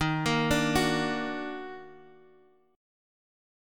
EbmM7 chord